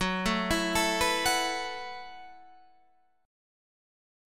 F#m11 chord